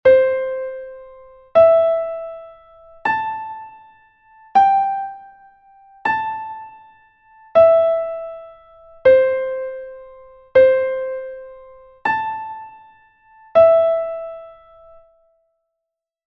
note recognition exercise 2